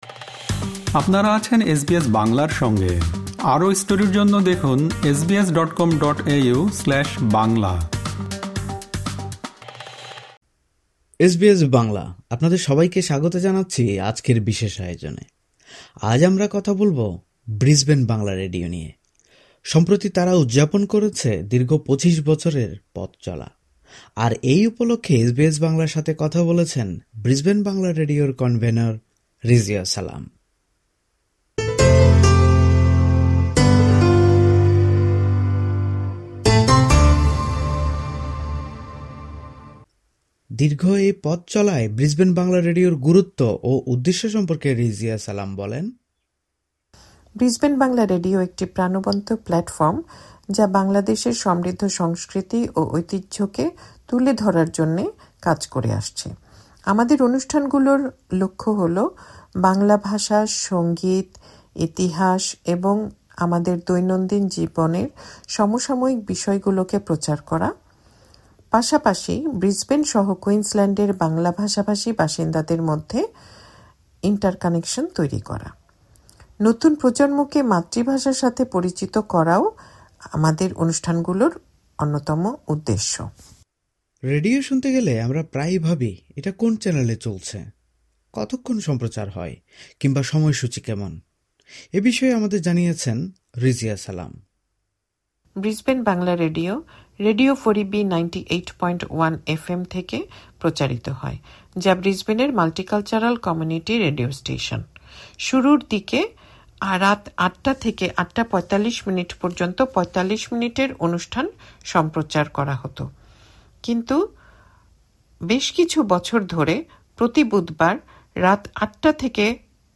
ব্রিসবেন বাংলা রেডিও সম্প্রতি উদযাপন করেছে দীর্ঘ ২৫ বছরের পথচলা। আর এ উপলক্ষে এসবিএস বাংলার সাথে কথা বলেছেন